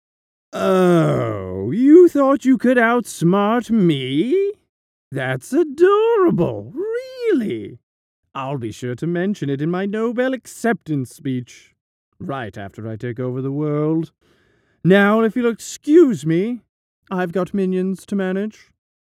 Standard Tokyo accent. 20s to 50s baritone range.
0820character.mp3